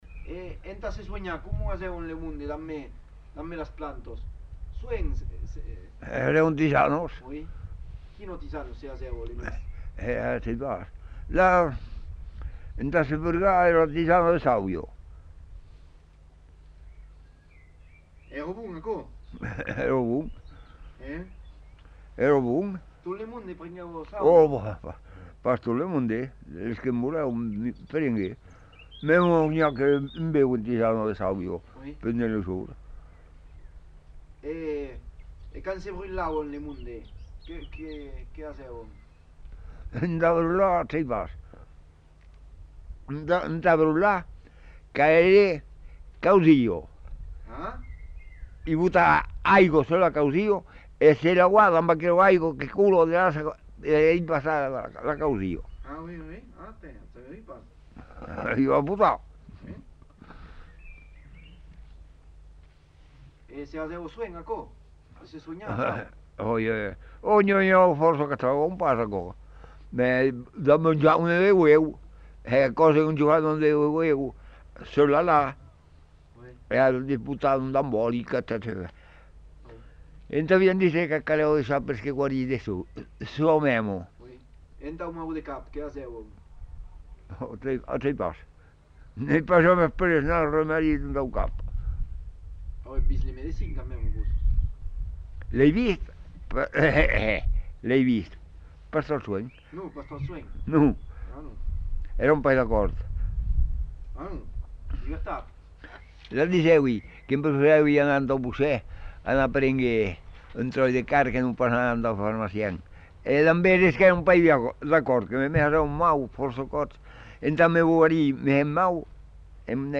Aire culturelle : Savès
Lieu : Montadet
Genre : témoignage thématique